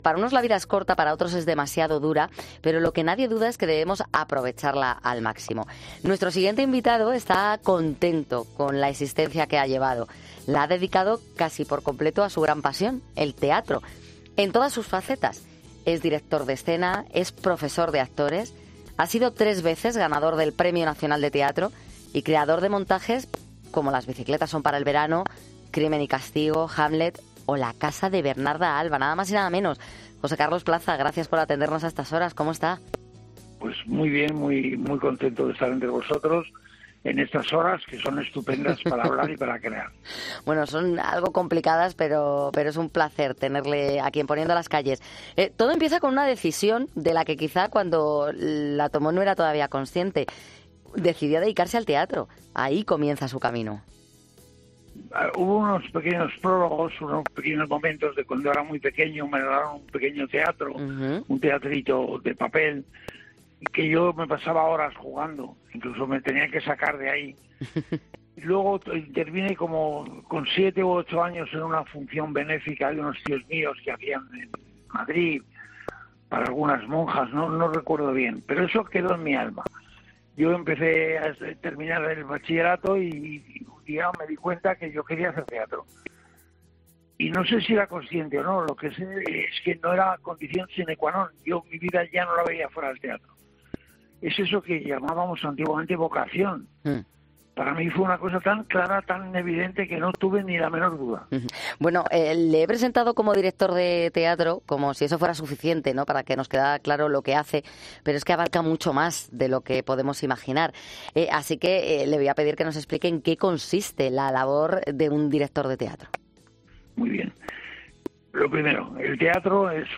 En Poniendo las Calles hemos podido charlar con José Carlos Plaza, toda una eminencia del teatro después de haber ganado 3 veces el Premio Nacional...